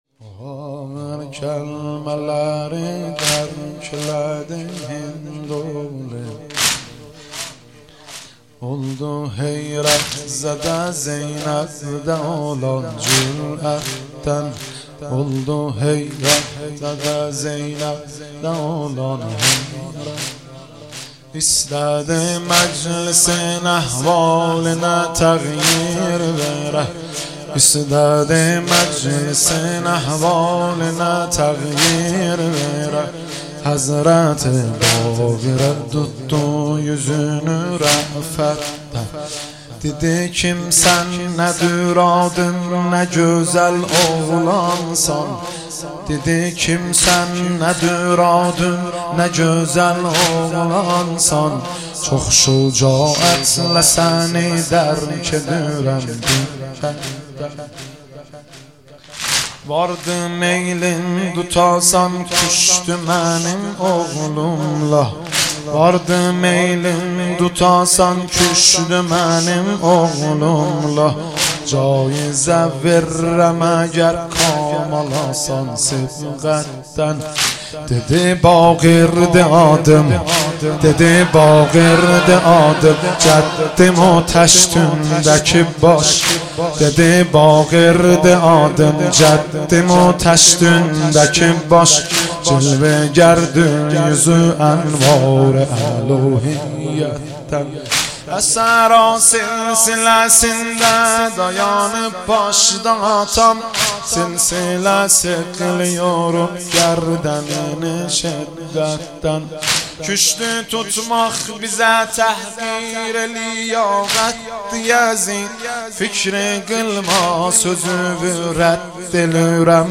مداحی شهادت امام محمد باقرمهدی رسولی | یک نت